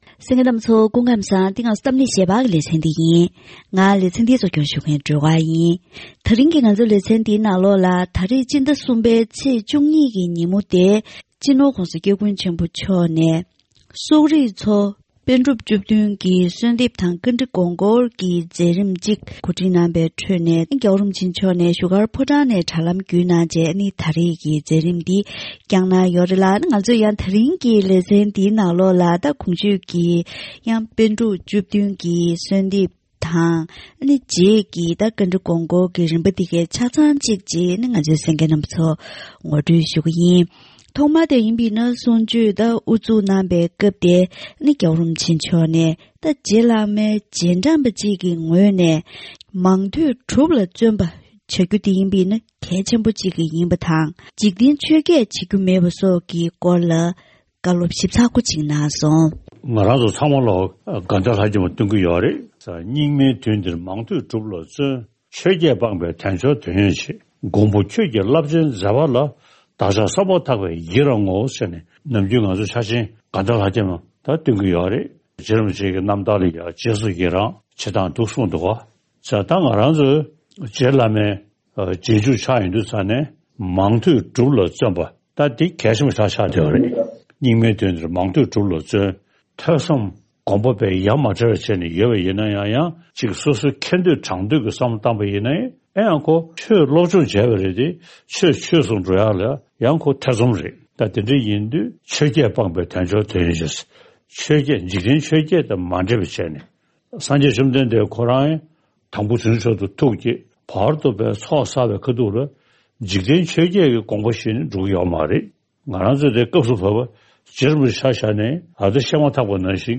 ༧གོང་ས་མཆོག་གིས་བཞུགས་སྒར་ཕོ་བྲང་ནས་དྲ་ལམ་བརྒྱུད་སོག་རིགས་ཀྱི་དད་ལྡན་པ་ཚོར་པཎ་གྲུབ་བཅུ་བདུན་གྱི་གསོལ་འདེབས་ཀྱི་ལྗགས་ལུང་སྩལ་བ།